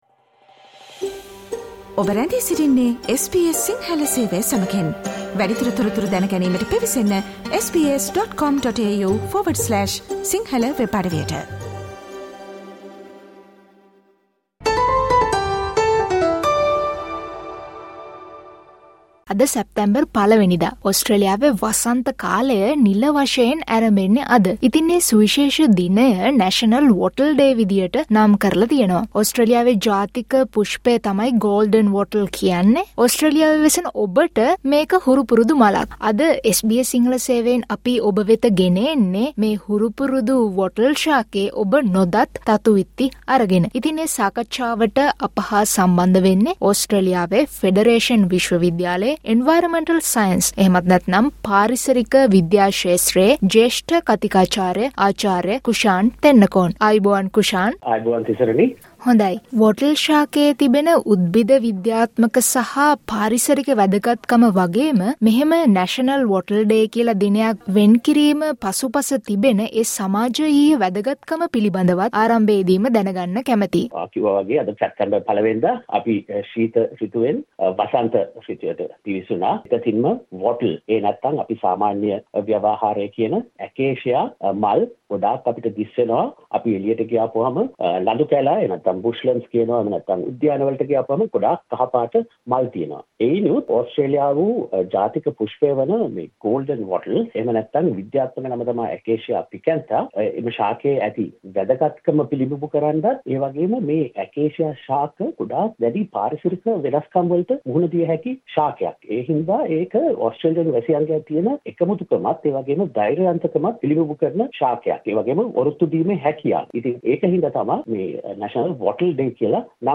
SBS සිංහල සේවය විසින් පුරුදු මලක නුපුරුදු පාරිසරික හා සමාජයීය තතු විත්ති ගෙන එන මෙම සාකච්ඡාවට සවන් දෙන්න.